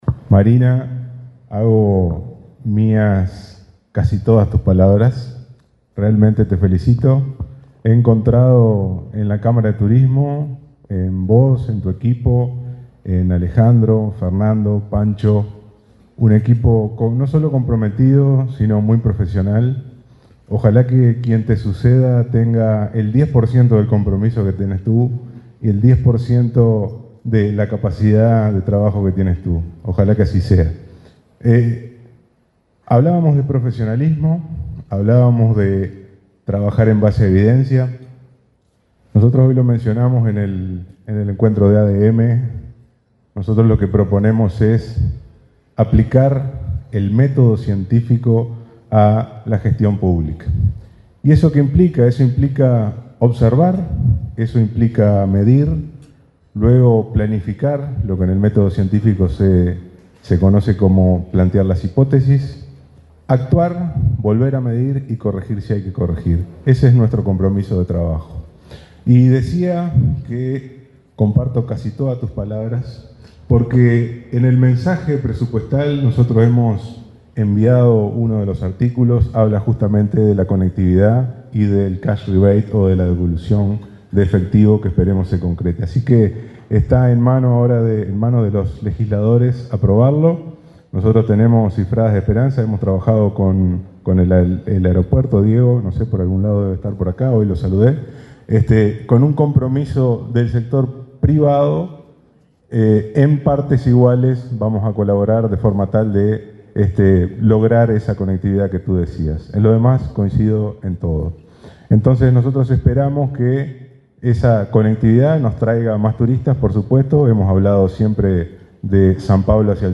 El ministro de Turismo, Pablo Menoni, se expresó durante la ceremonia de celebración del Día Mundial del Turismo, organizado por la Cámara Uruguay de